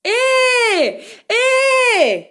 Mujer llamando la atención
interjección
locución
mujer
Sonidos: Voz humana